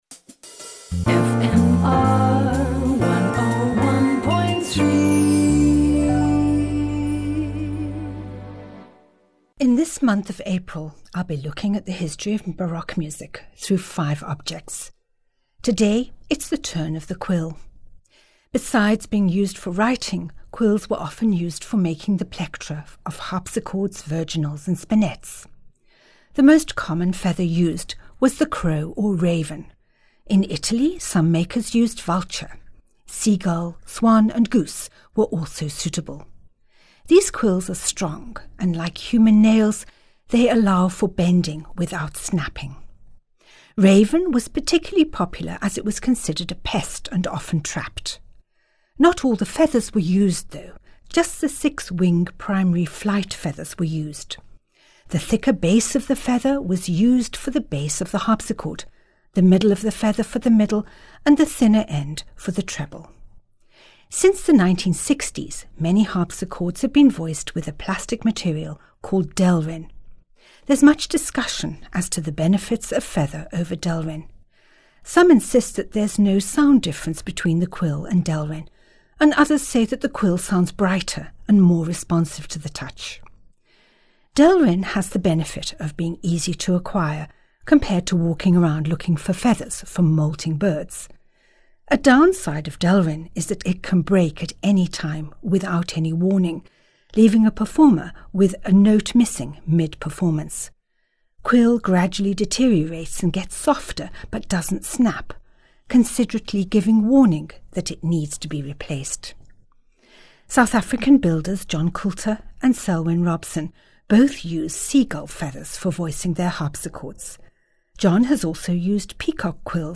From the esteemed Handel having his life saved by his jacket button while duelling to the latest discoveries of Baroque scores in dusty attics. Each weekly Bon Bon is accompanied by a piece of Baroque music which ties in with the story.